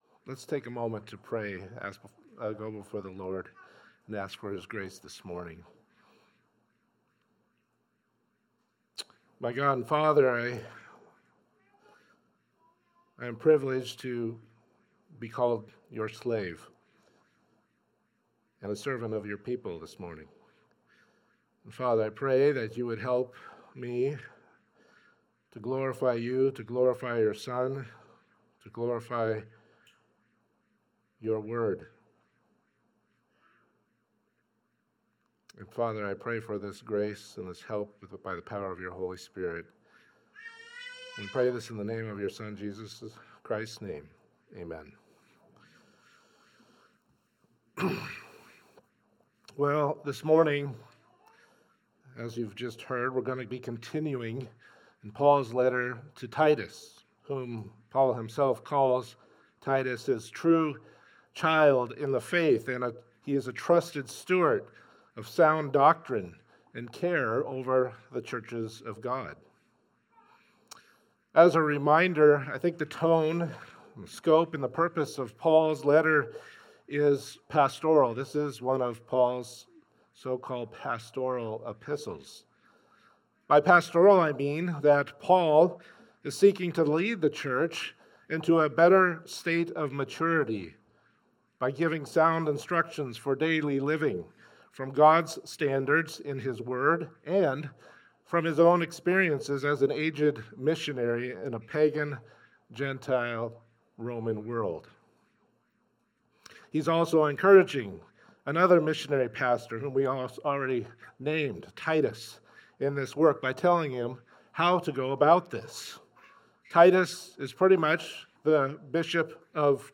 Titus Passage: Titus 1:8 Service Type: Sunday Service « “God Our Overseer” “Pastoral Qualifications